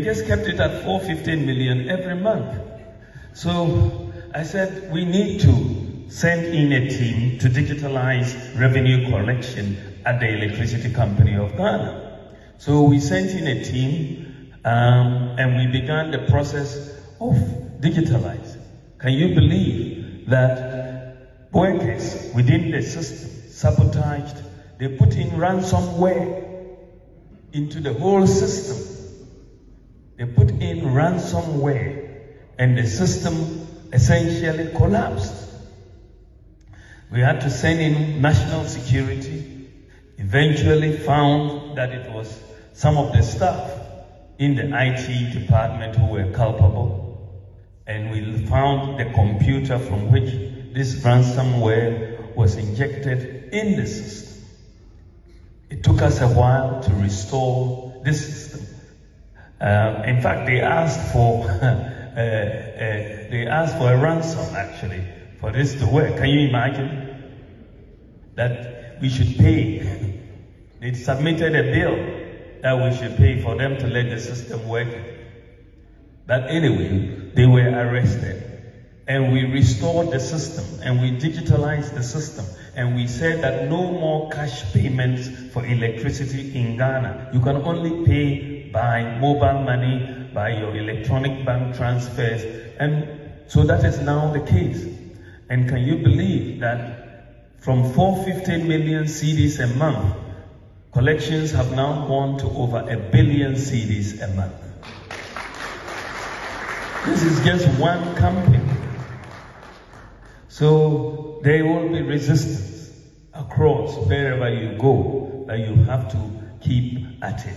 Dr Bawumia who has been at the front of the digitalisation drive disclosed this at the 2024 edition of the annual AGM of Anti-corruption Agencies in Africa.